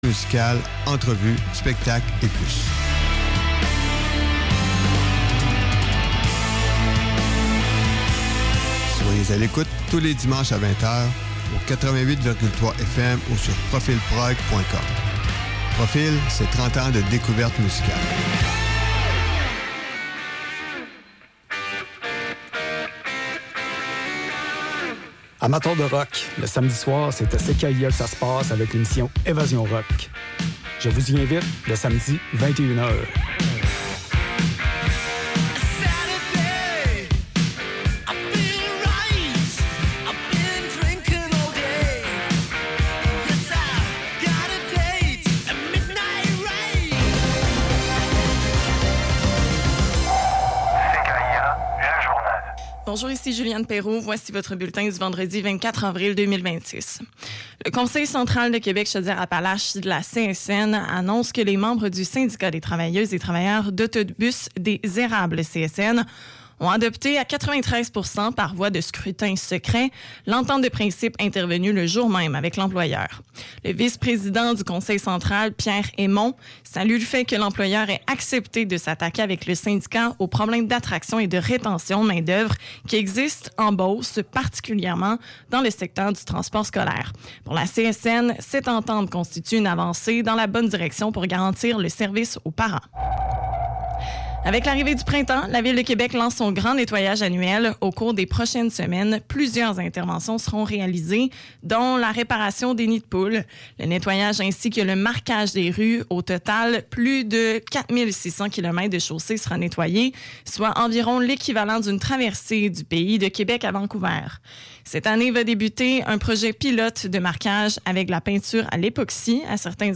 Revue de presse liée à l’actualité, aux changements climatiques et à la surconsommation, principalement dans Le Devoir.